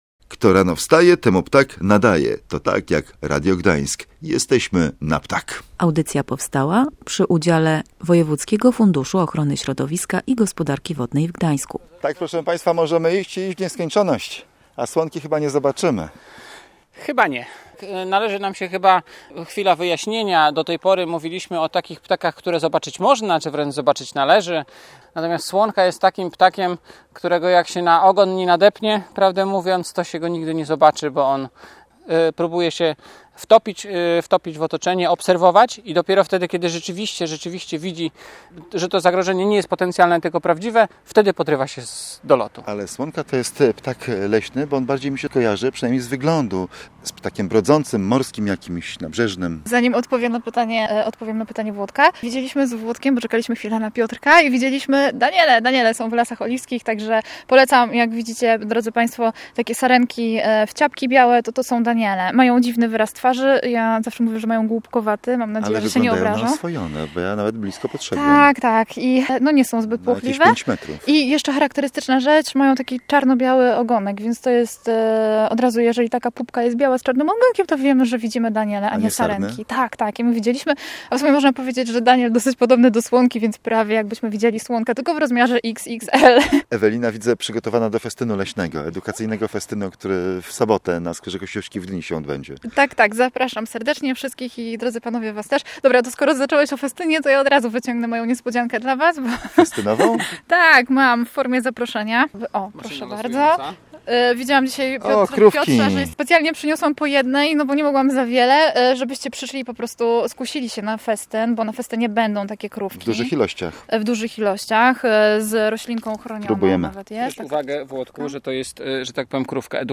Um cyk, um cyk, um cyk, pssssyt… Tak śpiewa słonka.